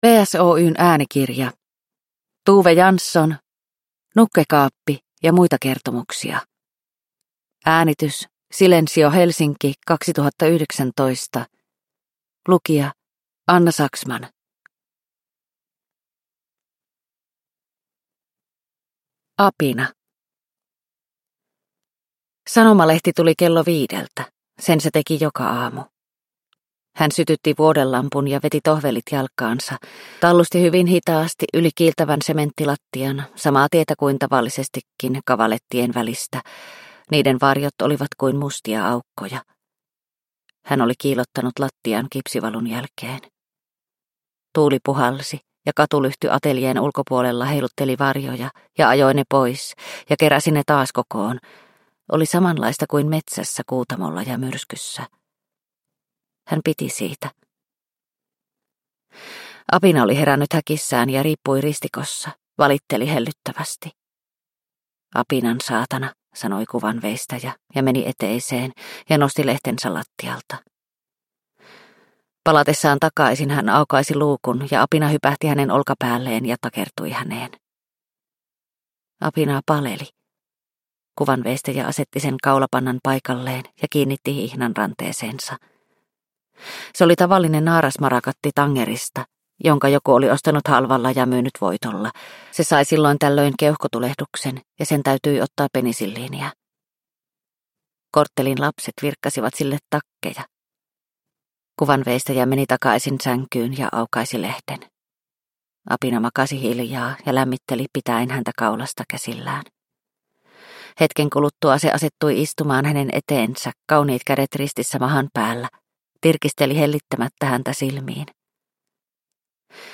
Kaksitoista novellia, seurallisin äänenpainoin kerrottua tarinaa, joiden poimuista ihmisen alastomuus paljastuu.